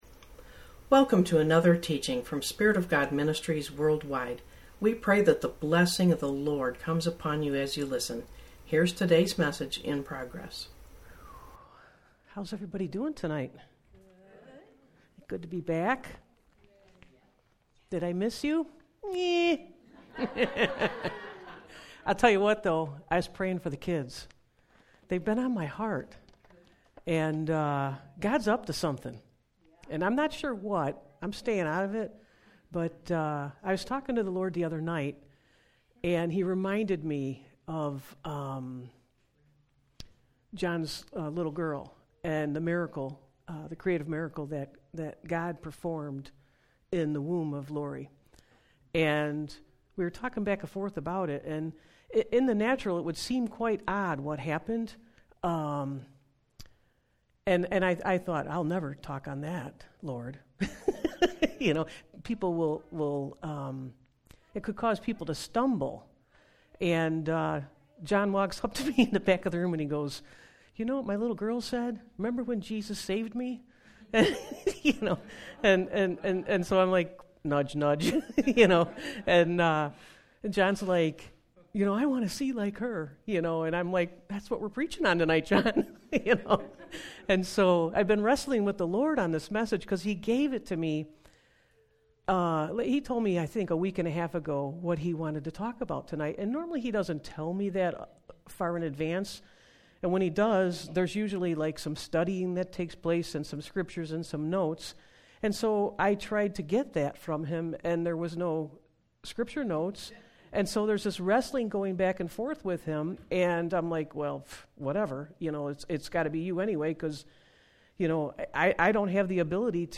Sermons | Spirit Of God Ministries WorldWide